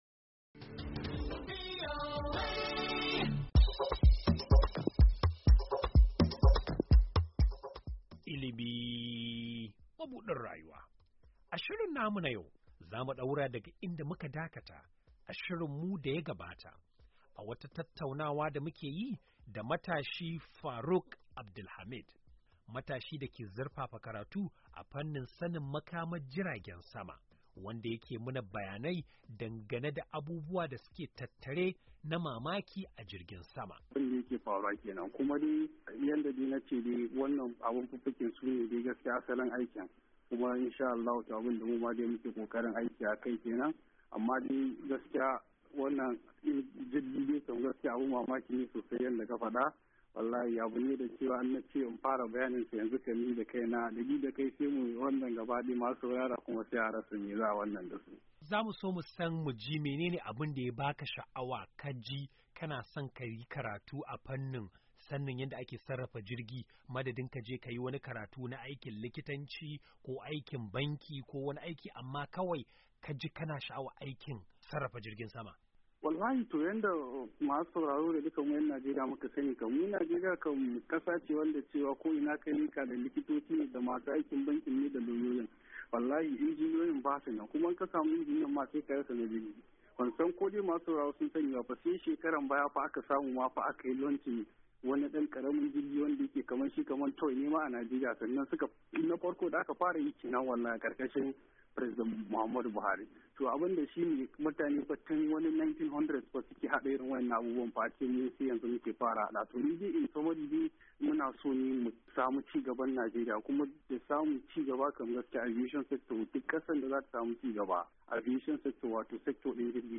Don jin cikakkiyar tattaunawar mu da matashi